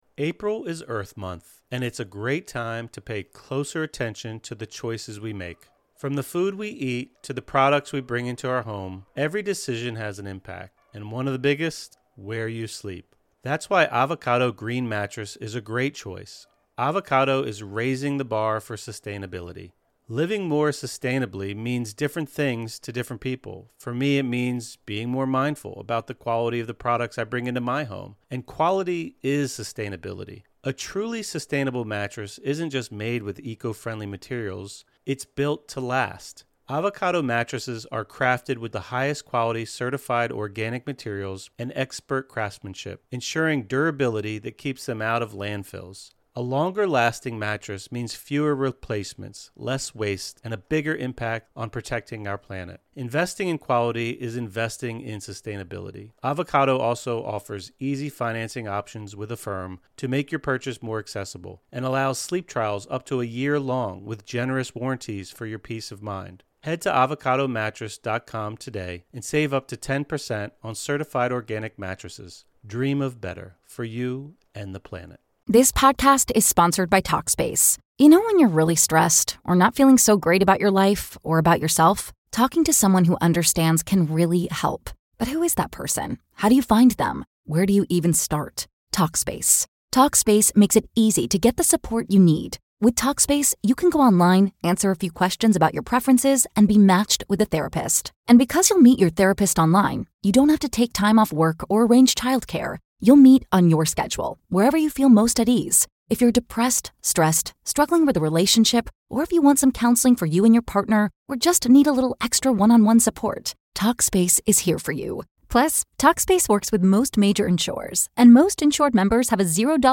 10 Minute Grounding Meditation | Relax and Recharge (; 06 Apr 2025) | Padverb
Take 10 minutes out of your day when you may be feeling stressed or overwhelmed to practice this short guided meditation to help you balance your energy and get grounded. Enjoy a time of curious introspection, noticing your breath and your body, then inviting in relaxation and ease.